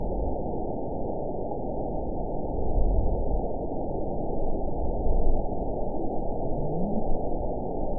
event 912175 date 03/19/22 time 21:26:00 GMT (3 years, 2 months ago) score 9.43 location TSS-AB01 detected by nrw target species NRW annotations +NRW Spectrogram: Frequency (kHz) vs. Time (s) audio not available .wav